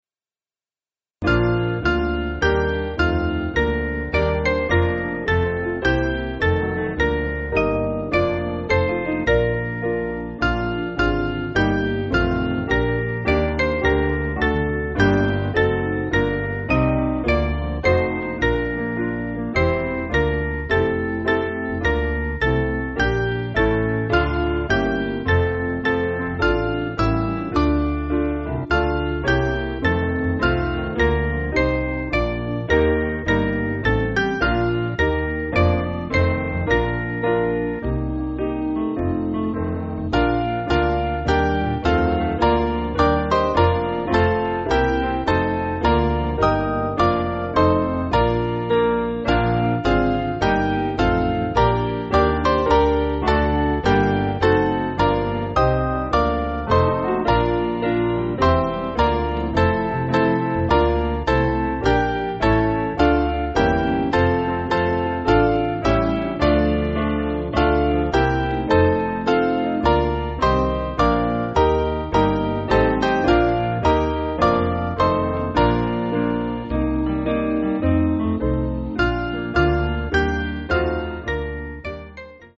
Mainly Piano
(CM)   4/Bb-B